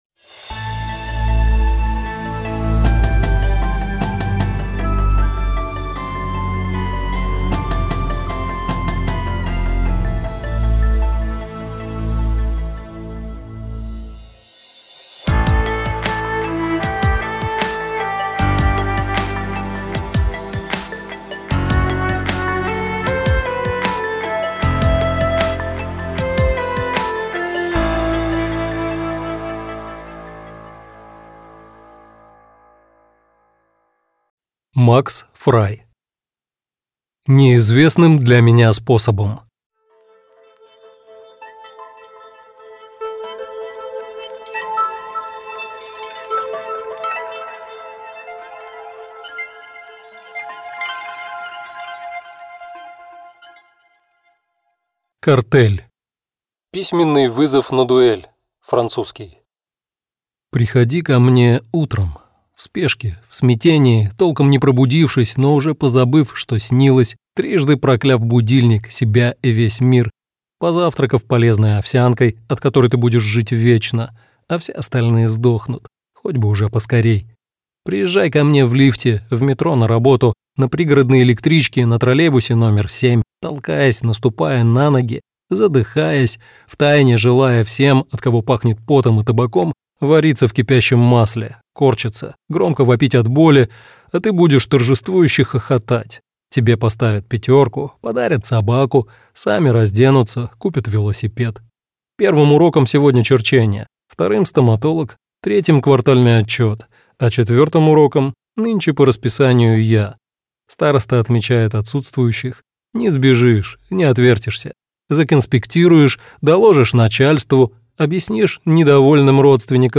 Аудиокнига Неизвестным для меня способом - купить, скачать и слушать онлайн | КнигоПоиск